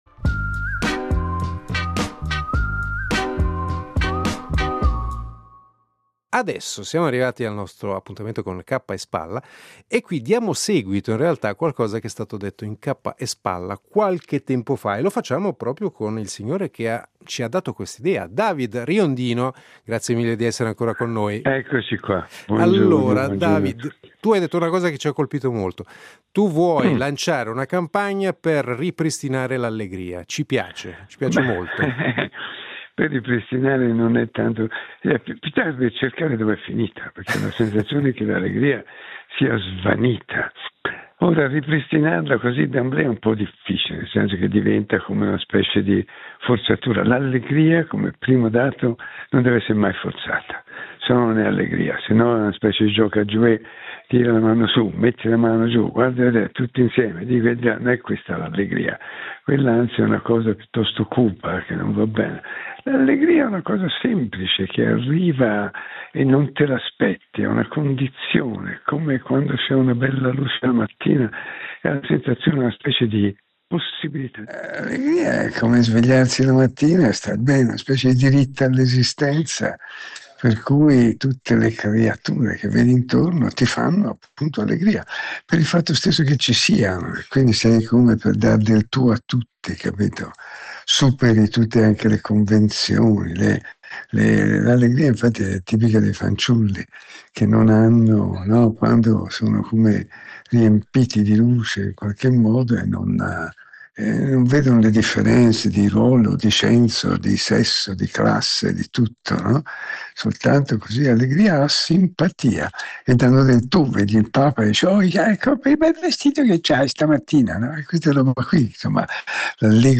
L’editoriale del giorno firmato da Davide Riondino